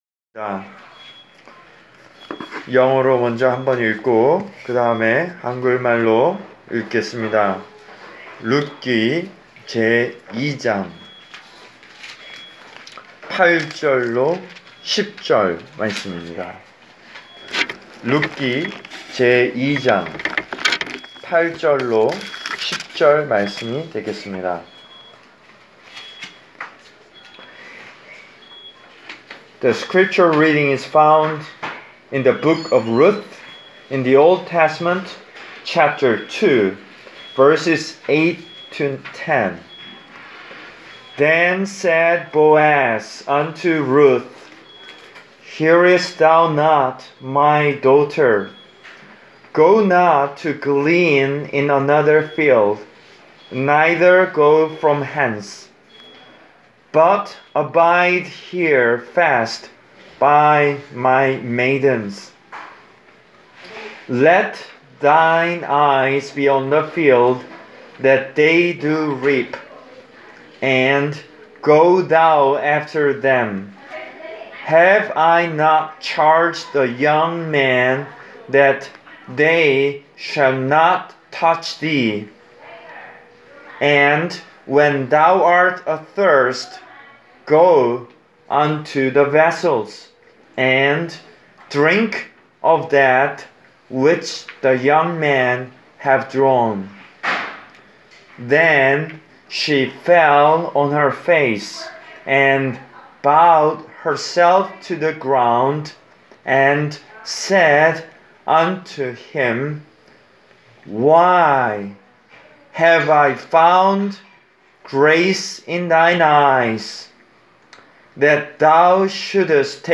Preached for: Country Arch Care Center, Pittstown, N.J.